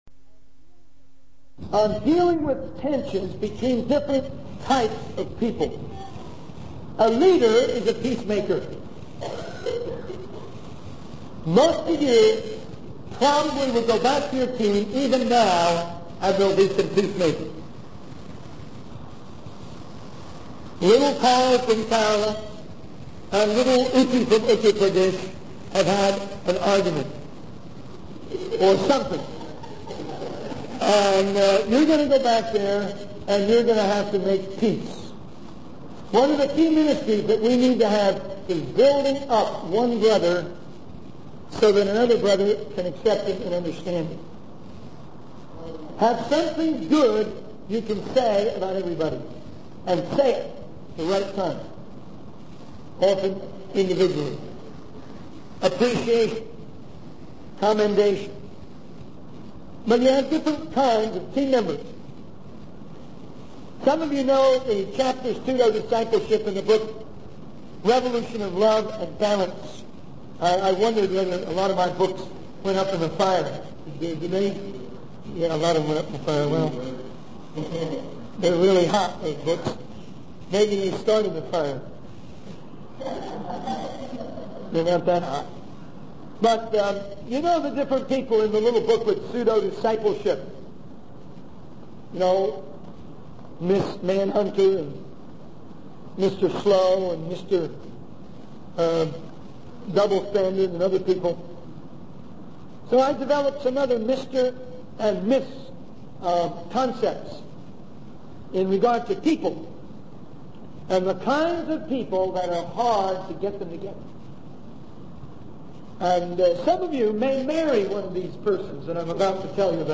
In this sermon, the speaker emphasizes the importance of tailoring the message of the word of God to different cultures.